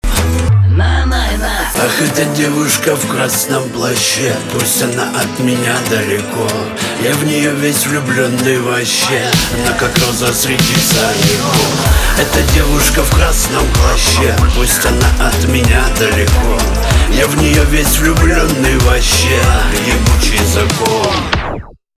шансон
гитара